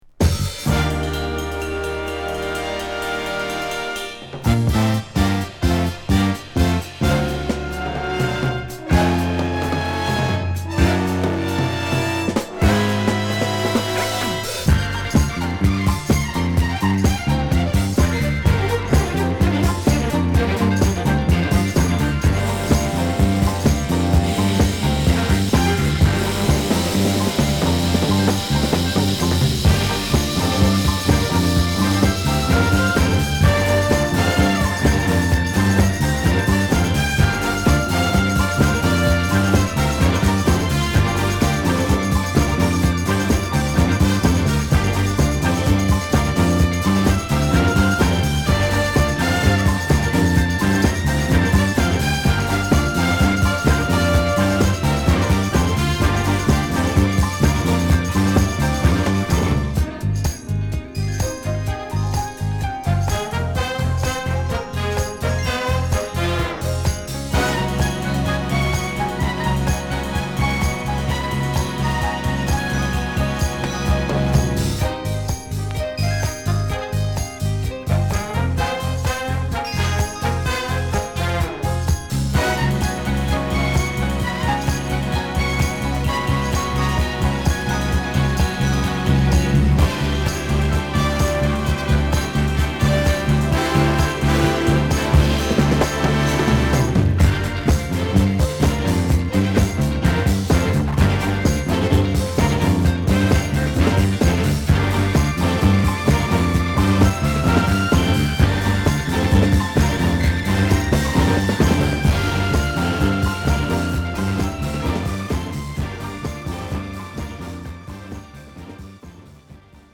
スリリングなインスト・ファンク
グルーヴィーなグッドチューン